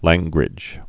(lănggrĭj)